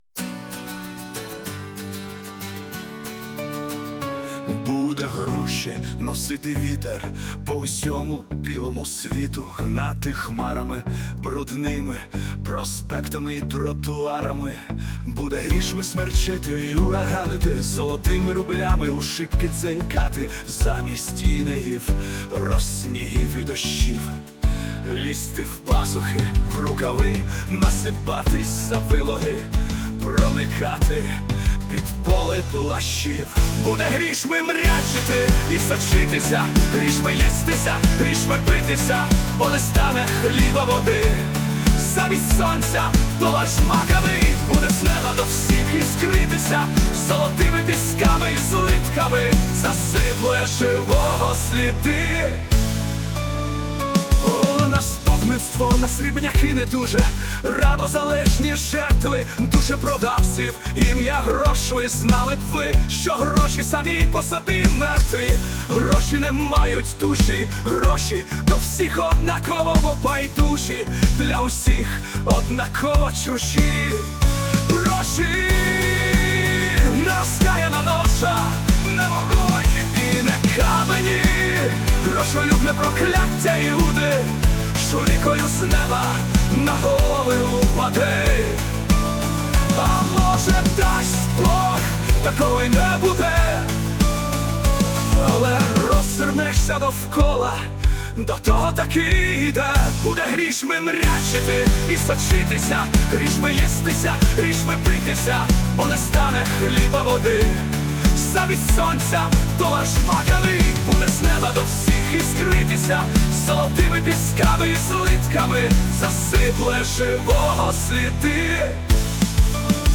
музику і виконання згенеровано ШІ